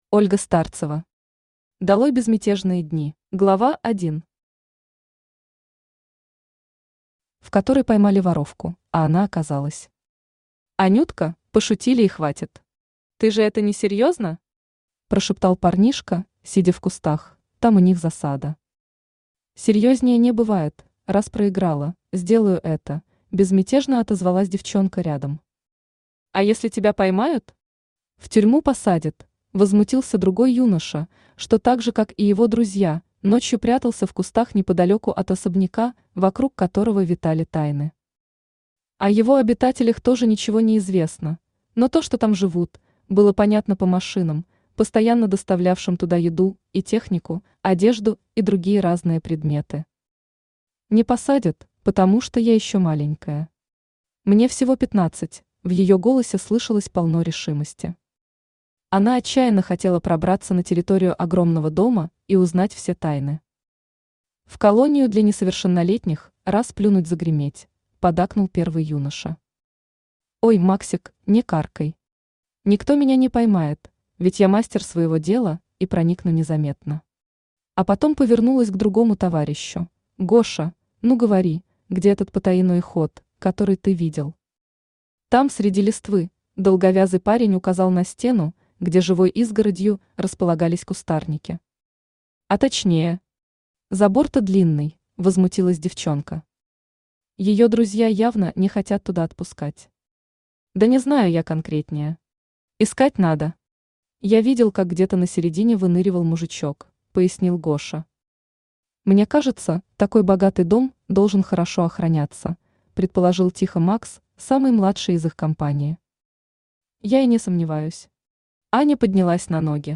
Аудиокнига Долой безмятежные дни | Библиотека аудиокниг
Aудиокнига Долой безмятежные дни Автор Ольга Старцева Читает аудиокнигу Авточтец ЛитРес.